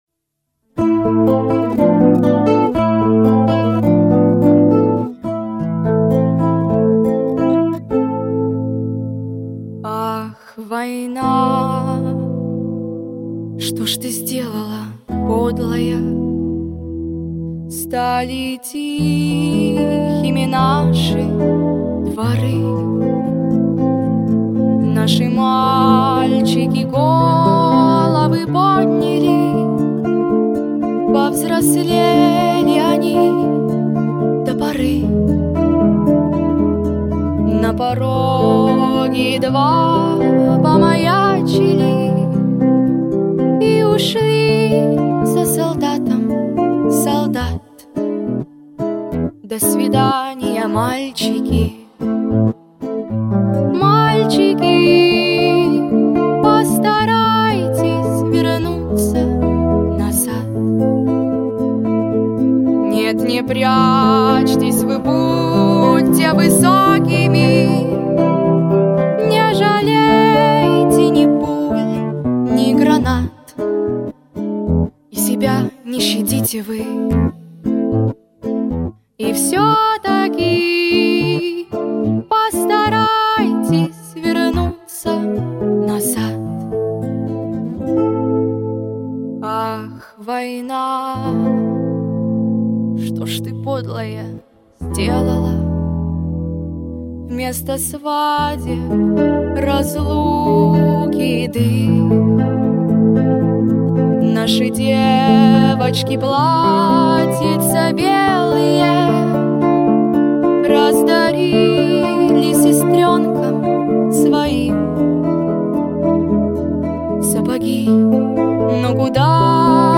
• Категория: Детские песни
военные песни